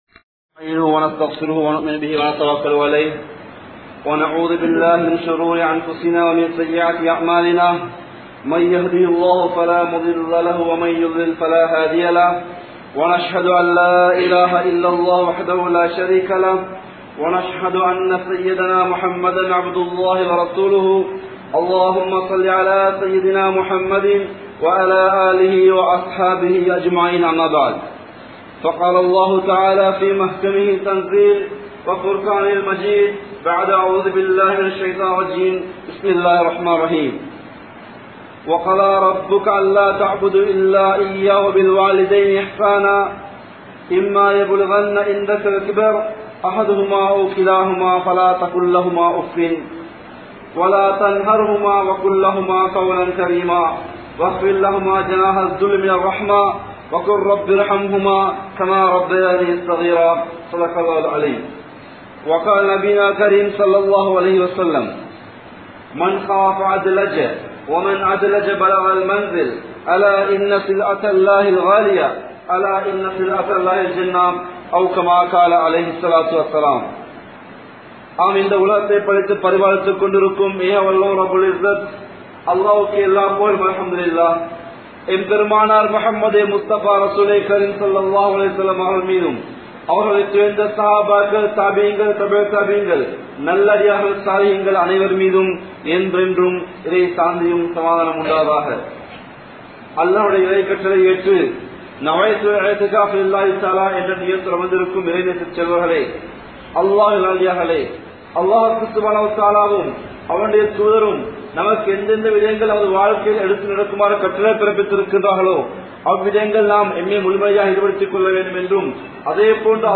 Pettroarhalin Perumathi (பெற்றோர்களின் பெறுமதி) | Audio Bayans | All Ceylon Muslim Youth Community | Addalaichenai
Welay Kada Jumua Masjidh